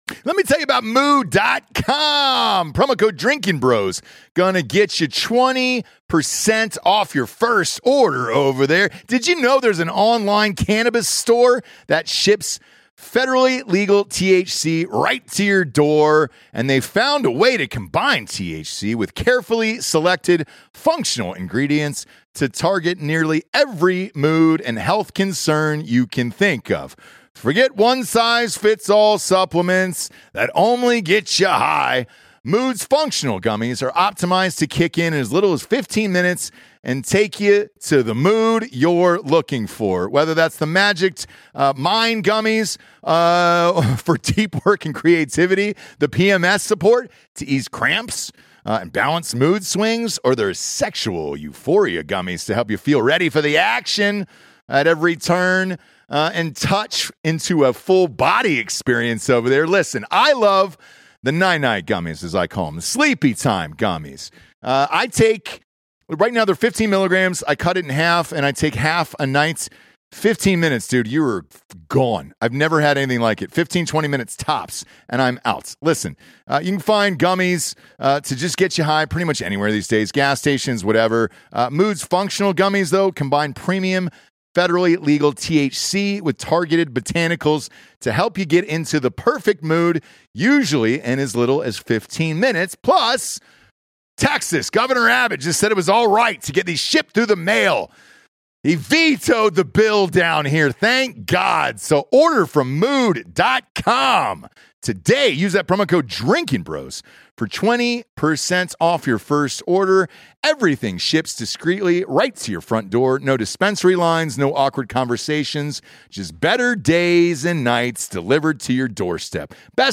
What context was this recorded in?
live from Las Vegas